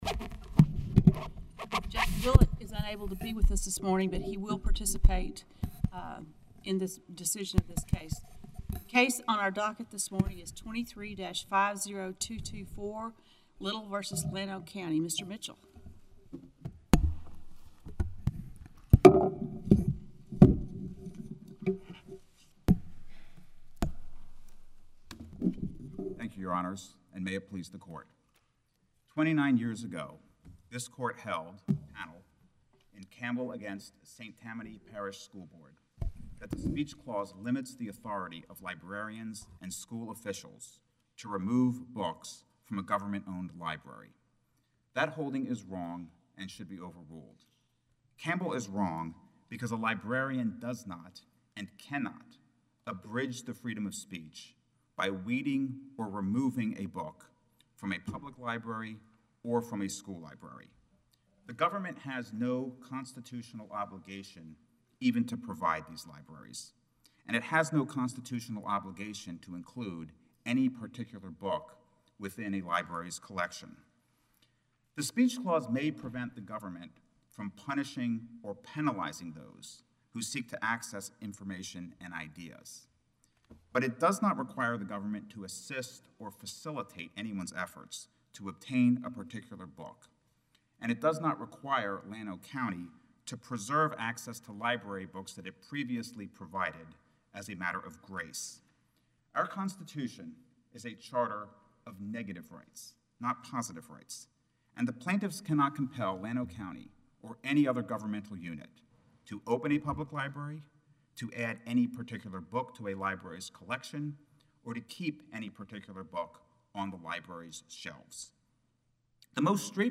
The hearing was Wednesday.